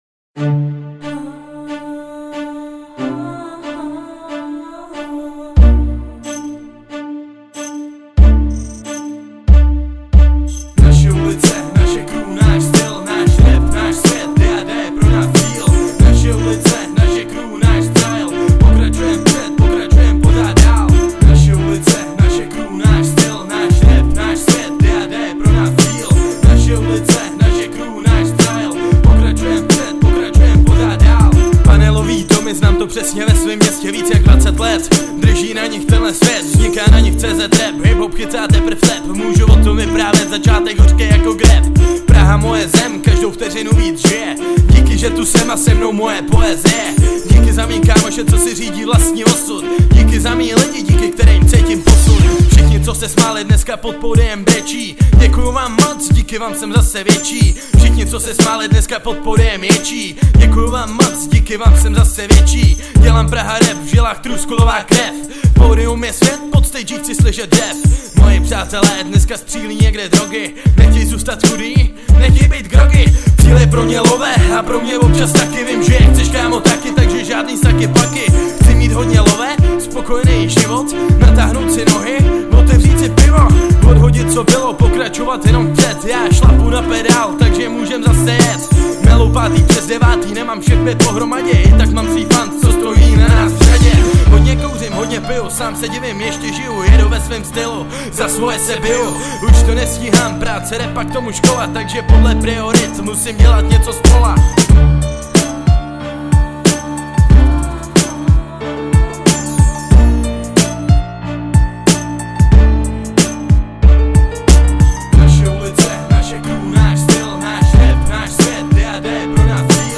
12 Styl: Hip-Hop Rok